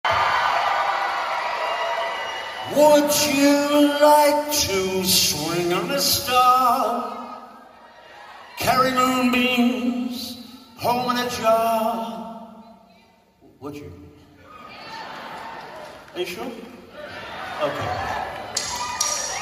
580 KB Opening words from Arkansas 2024 Taken from https